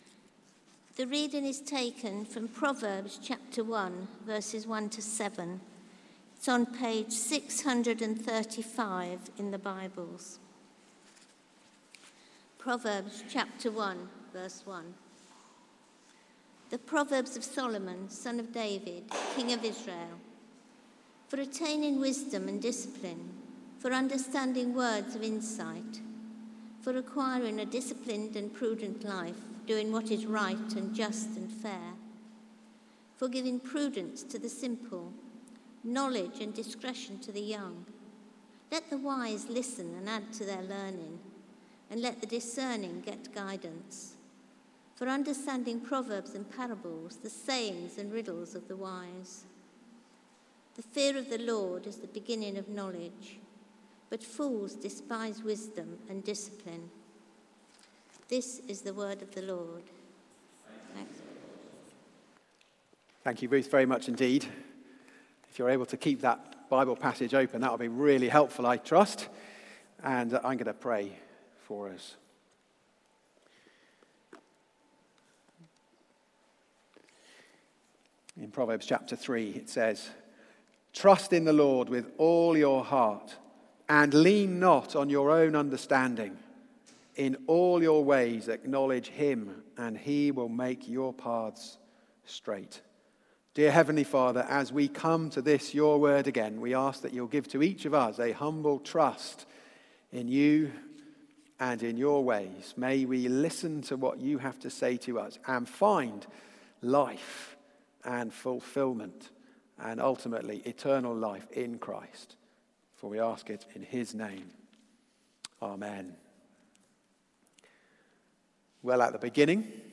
Proverbs: Living Well in this World Theme: Wisdom's Character Sermon